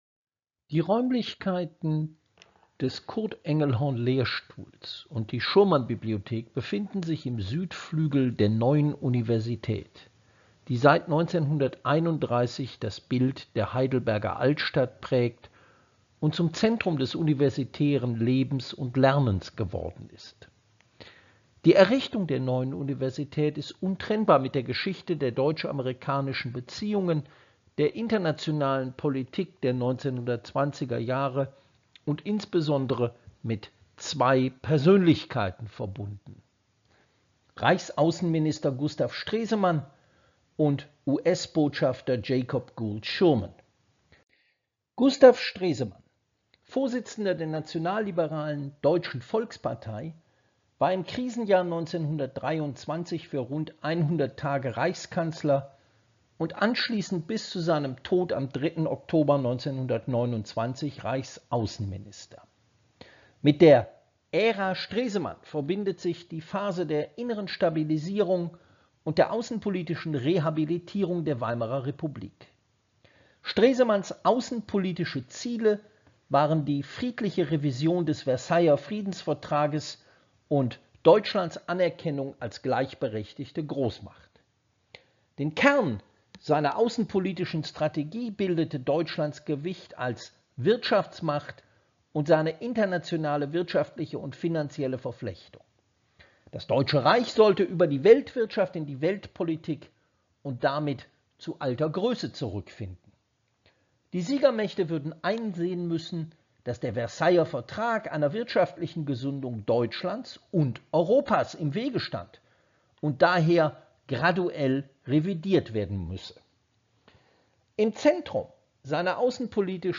Die Amerikaner und der Bau der Neuen Universität, Vortrag